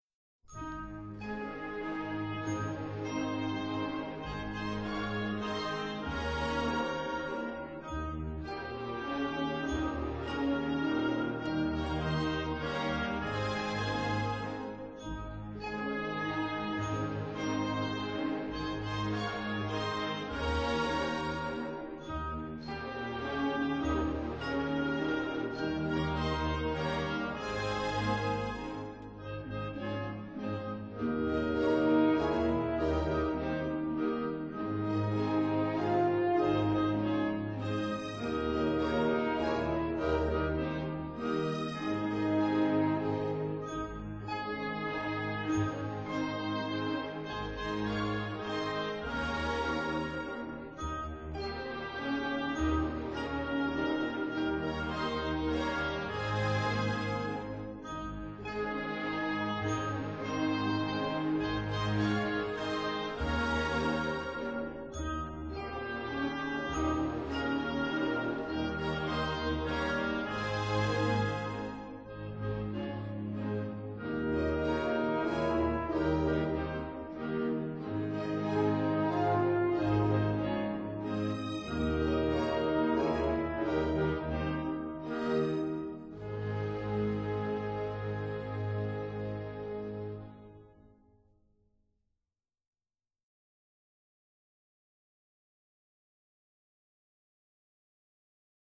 Symphonic Poem * [17'10]
soprano